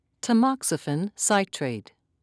(ta-mox'i-fen)